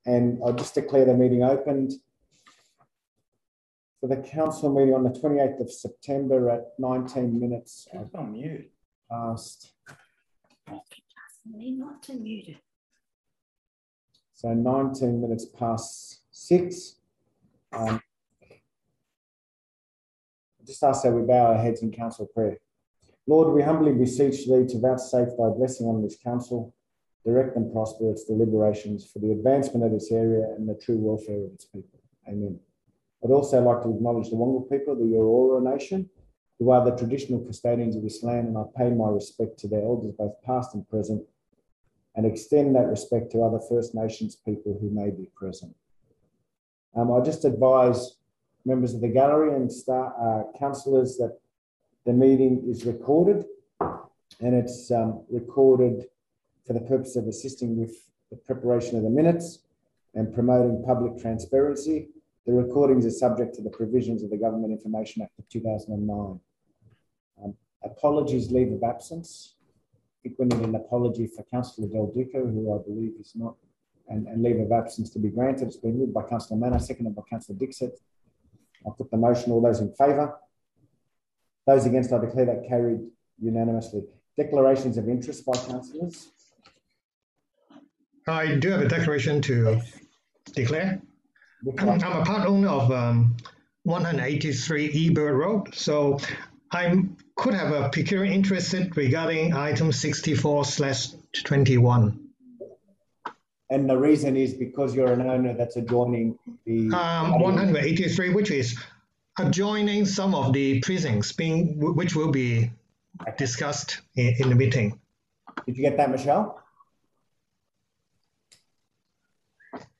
Council Meeting - 28 September 2021
Notice is hereby given that a meeting of the Council of Burwood will be held electronically on Tuesday, 28 September 2021.